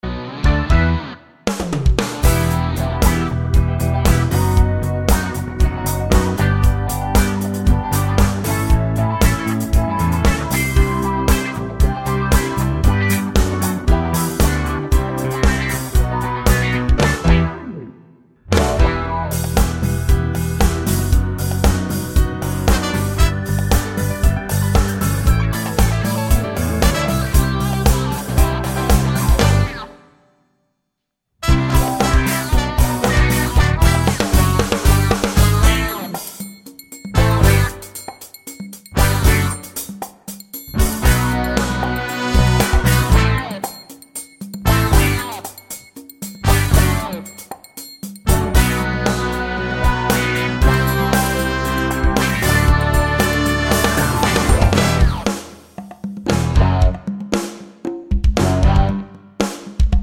no Backing Vocals Musicals 2:35 Buy £1.50